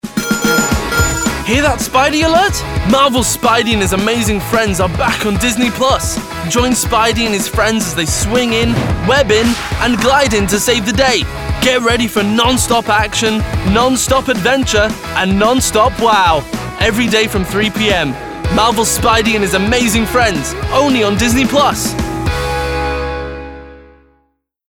Voice Reel
Disney Promo - Fun, Energised